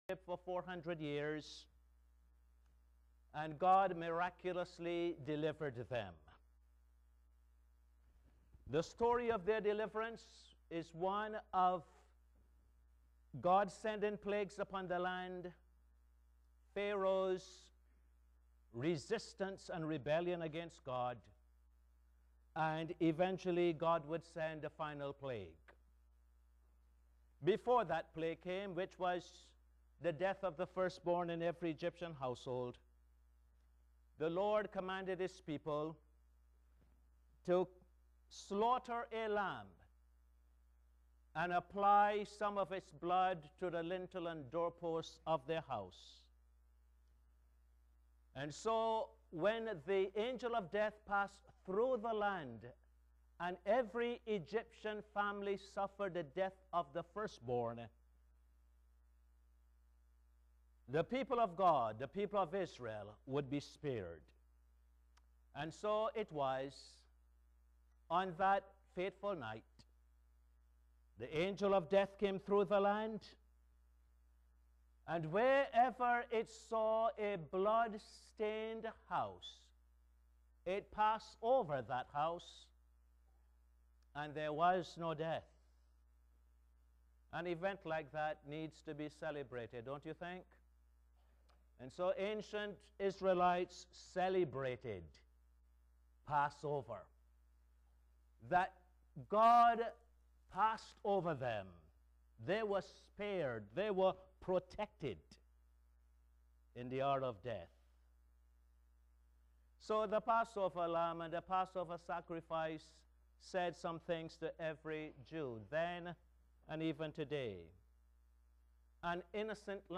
Posted in Sermons on 08.